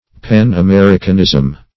pan-americanism.mp3